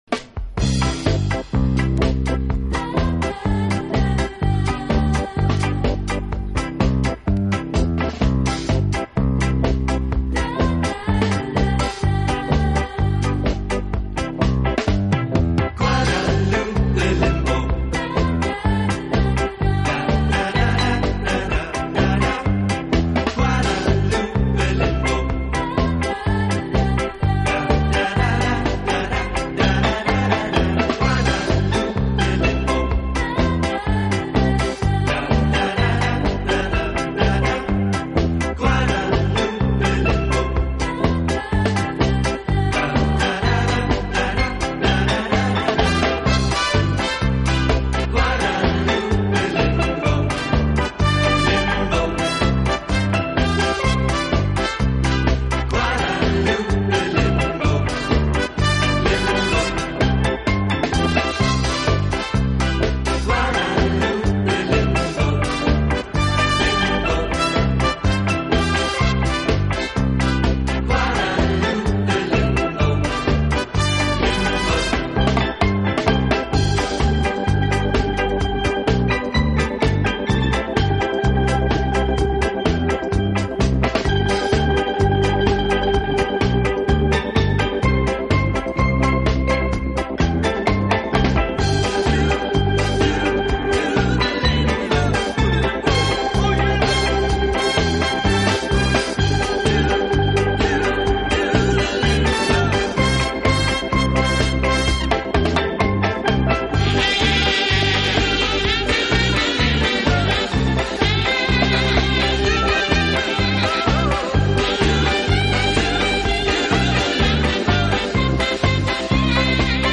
以擅长演奏拉丁美洲音乐而著称。